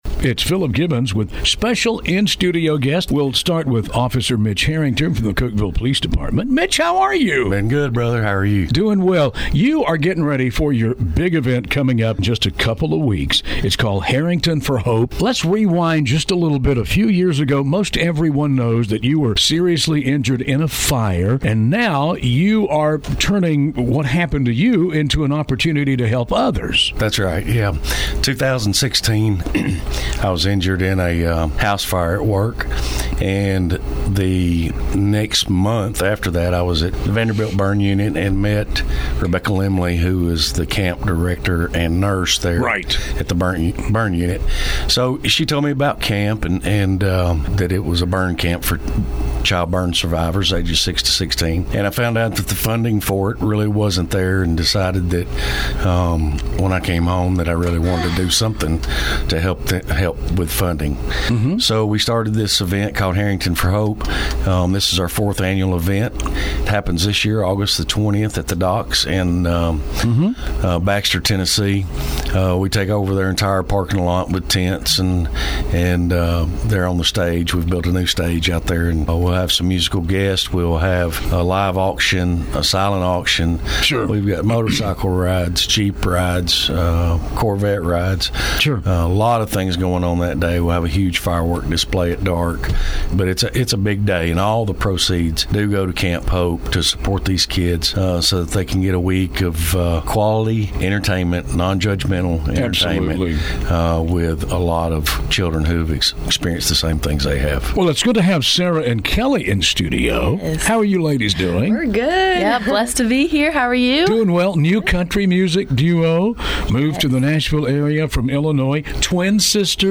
Interview with WGSQ Radio